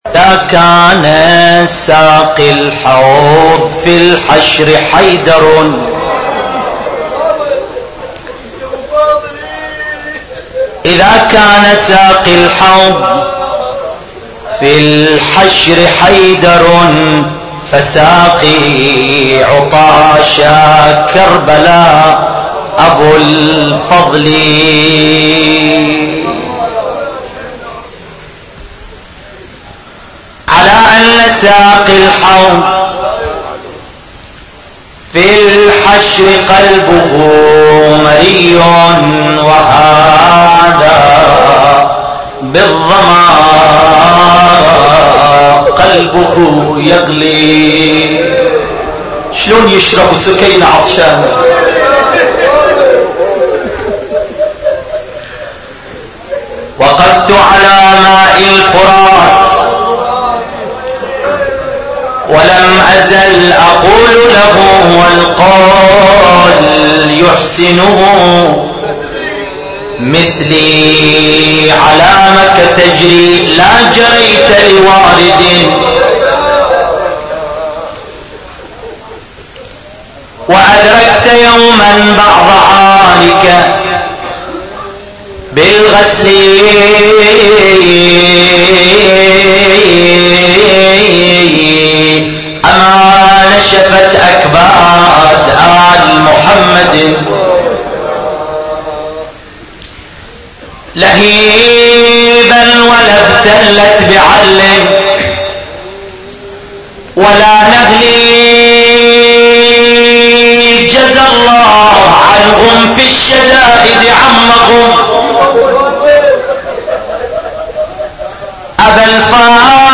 نواعي حسينية 6